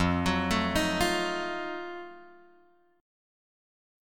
F7b9 chord {1 x 4 2 4 1} chord